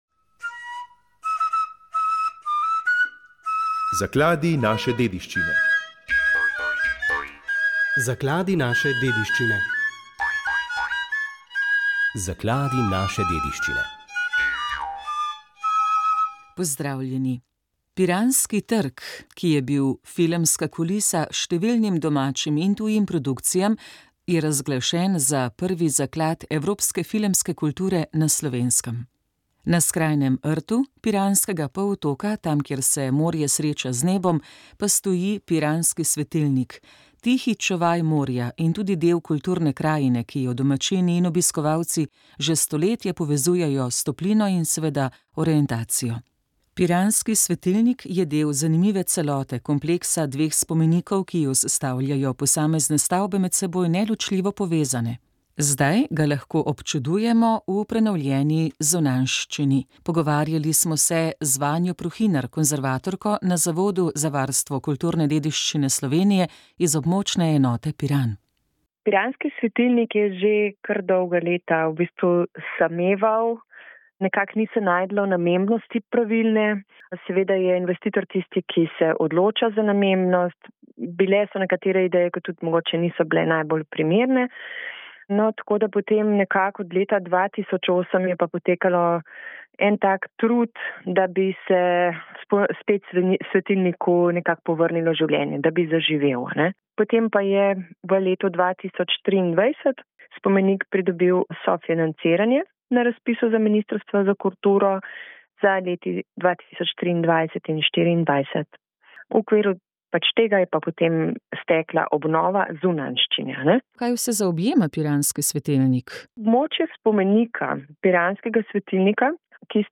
Prebrali smo nekaj odlomkov izknjige »Najti notranji mir«, Anselma Grüna.